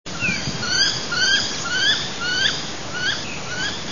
Karolinka - Aix Sponsa
głosy